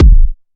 Subby Kick Drum Sample E Key 175.wav
Royality free kick drum single hit tuned to the E note. Loudest frequency: 134Hz
.WAV .MP3 .OGG 0:00 / 0:01 Type Wav Duration 0:01 Size 47,11 KB Samplerate 44100 Hz Bitdepth 16 Channels Mono Royality free kick drum single hit tuned to the E note.
subby-kick-drum-sample-e-key-175-tph.ogg